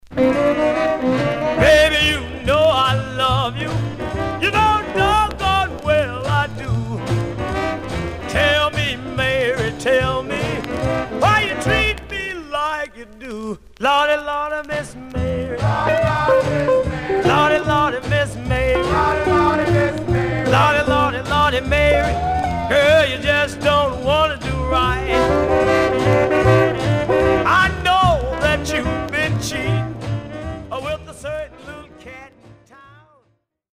Some surface noise/wear
Mono
Rythm and Blues